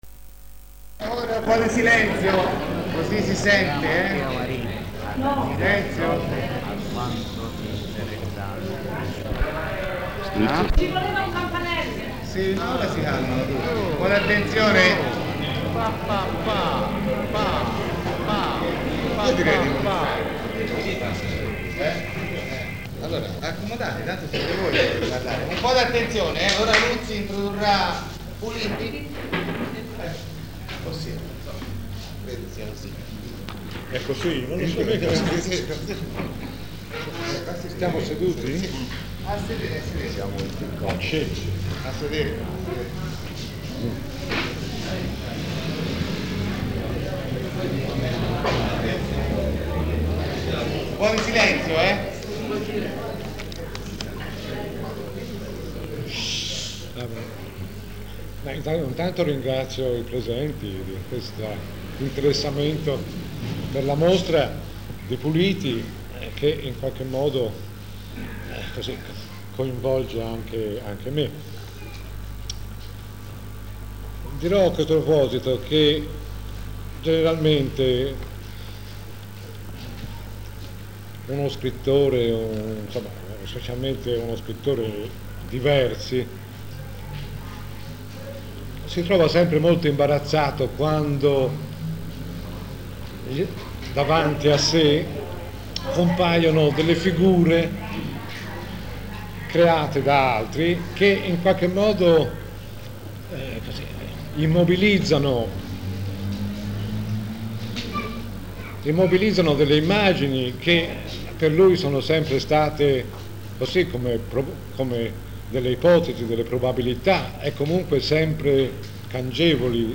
Incontro avvenuto nell’estate del 1975